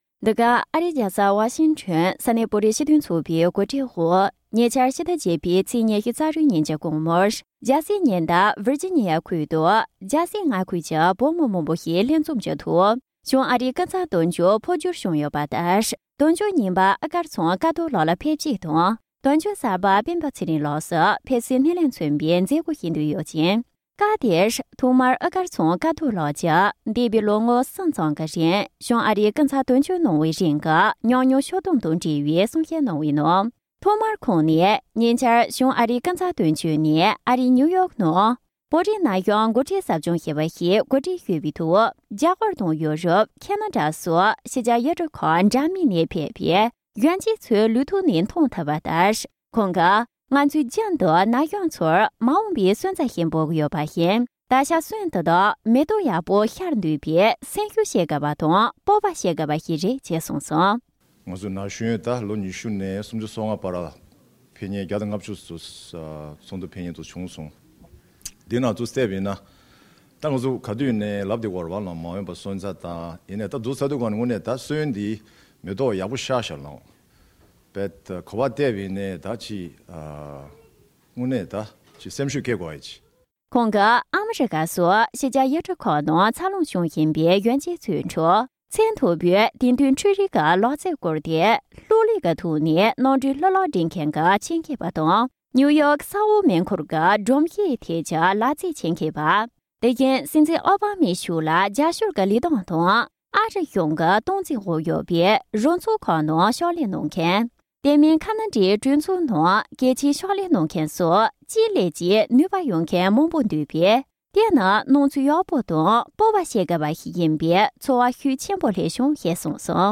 དོན་གཅོད་གསར་རྙིང་གཉིས་ནས་གཏམ་བཤད་གནང་བ།
སྒྲ་ལྡན་གསར་འགྱུར། སྒྲ་ཕབ་ལེན།